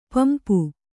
♪ pampu